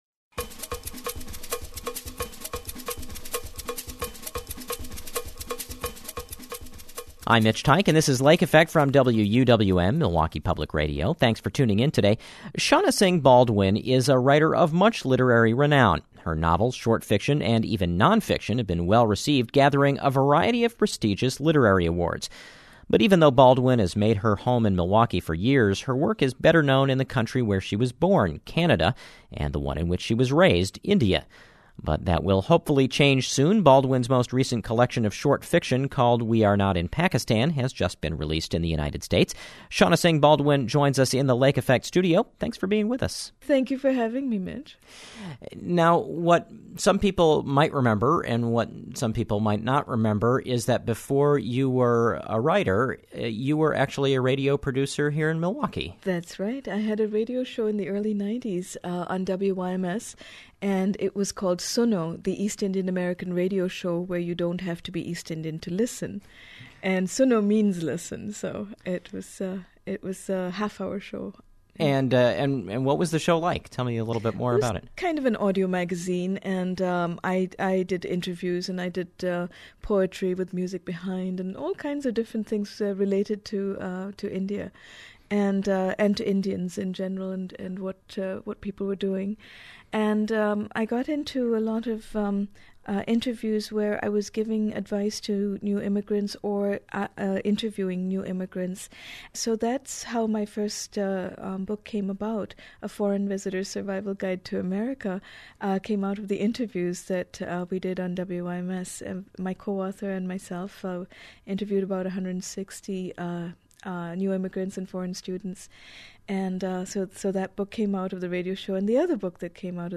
WUWM Radio Interview